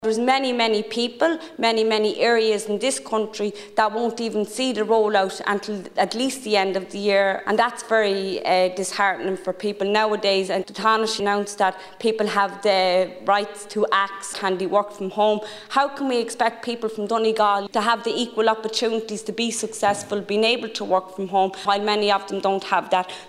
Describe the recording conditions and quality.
The Seanad has been told that giving people the right to ask if they can work from home is meaningless in many rural areas where the rollout of broadband is still a long way off.